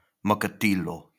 Makakilo (Hawaiian pronunciation: [məkəˈtilo]